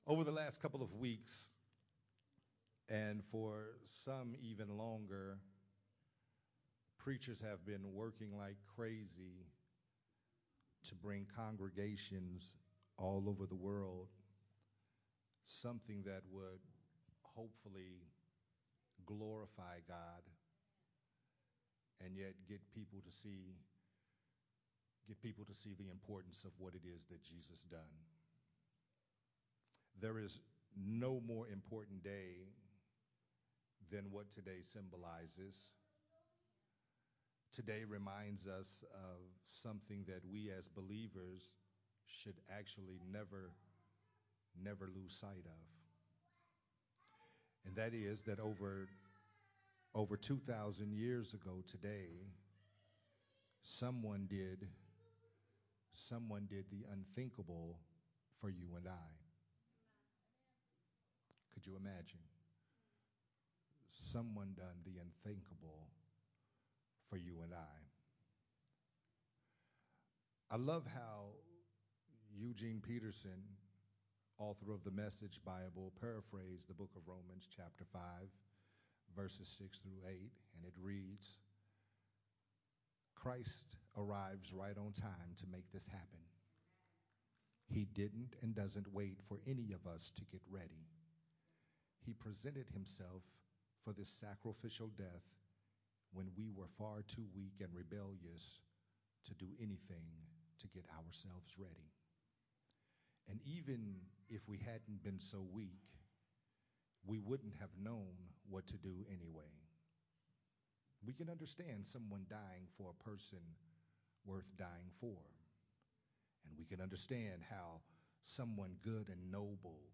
an Easter message
recorded at Unity Worship Center on April 9th, 2023.
Sunday Morning Worship Service